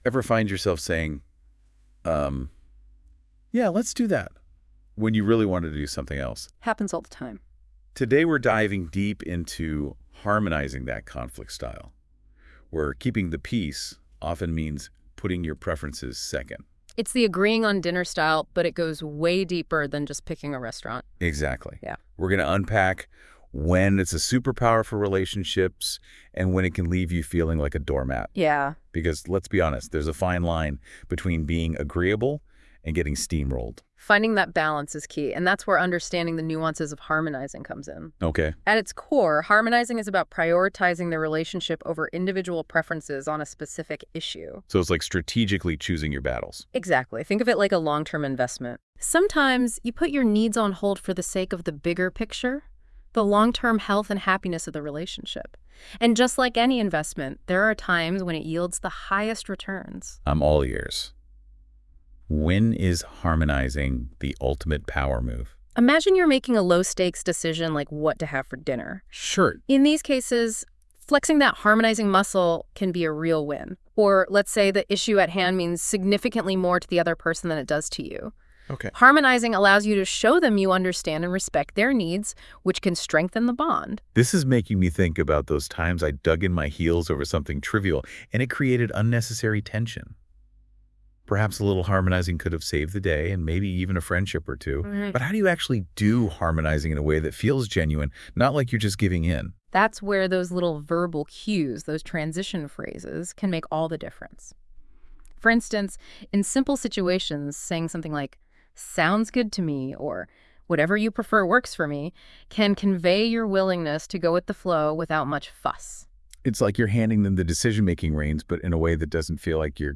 Hear this as an audio conversation created in Notebook LLM Let's start with a caution: Setting aside your own wishes just for the sake of the relationship is not always a good option.